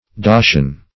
Search Result for " dacian" : The Collaborative International Dictionary of English v.0.48: Dacian \Da"cian\, a. Of or pertaining to Dacia or the Dacians.